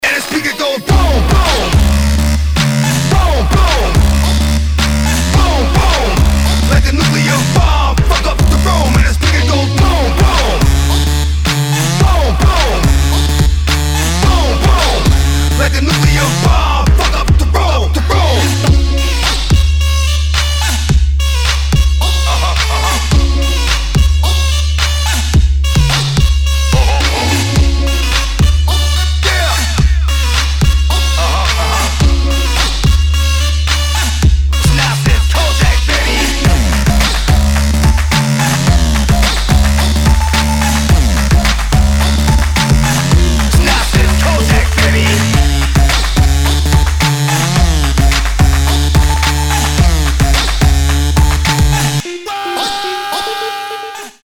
Trap
Rap